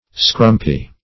scrumpy - definition of scrumpy - synonyms, pronunciation, spelling from Free Dictionary
scrumpy.mp3